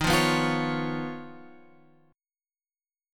D# 7th Suspended 2nd Sharp 5th